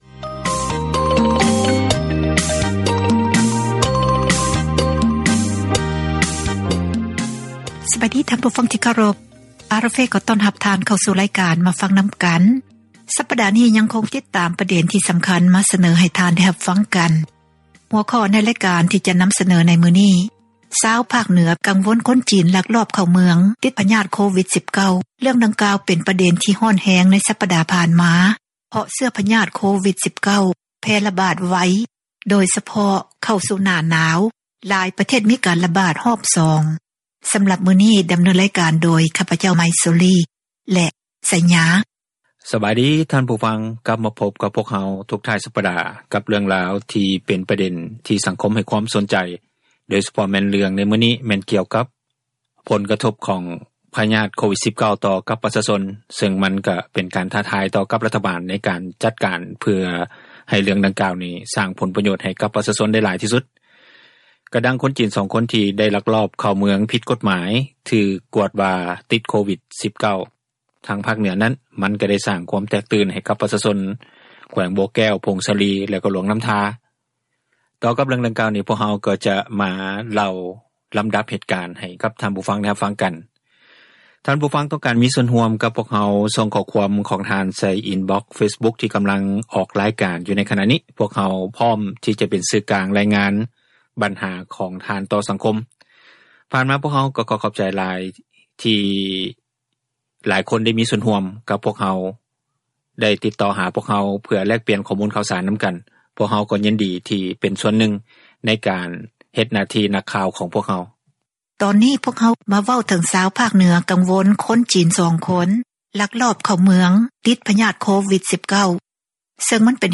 ການສົນທະນາ ໃນຫົວຂໍ້ ບັນຫາ ແລະ ຜົລກະທົບ ຢູ່ ປະເທດລາວ